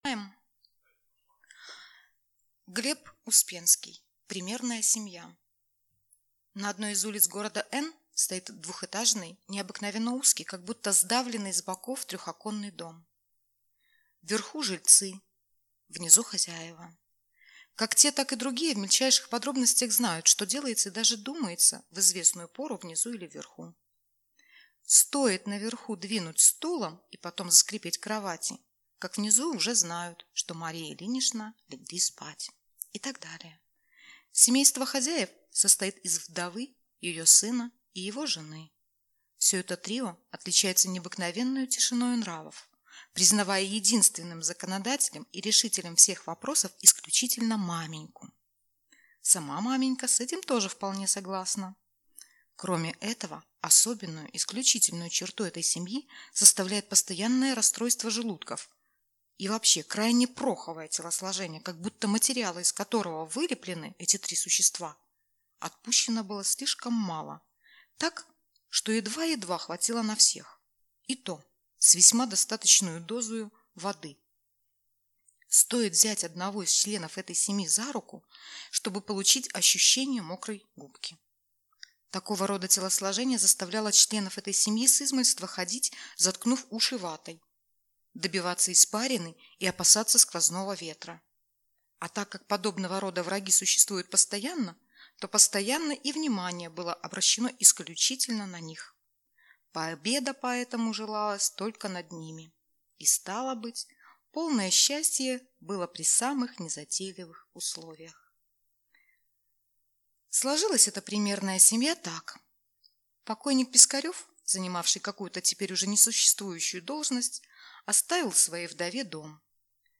Классические литературные произведения в исполнении сотрудников Центральной городской библиотеки им А.С. Пушкина